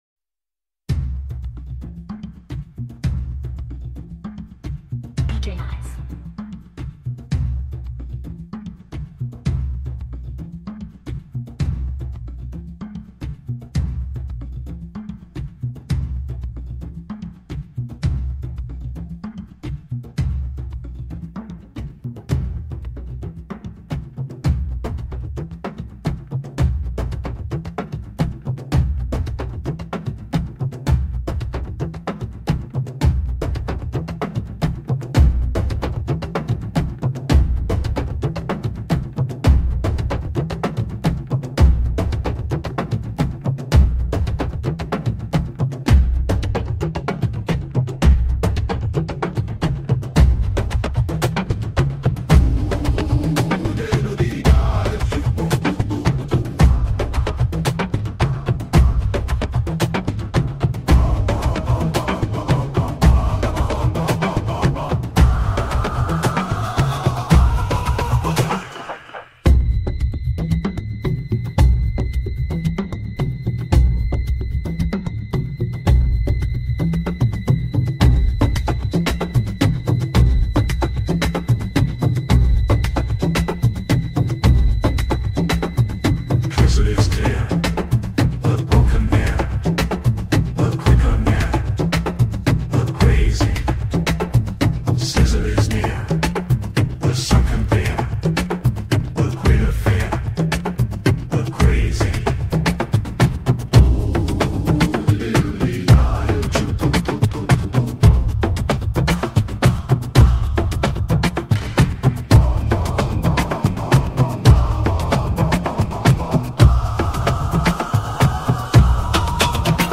AI Generated Music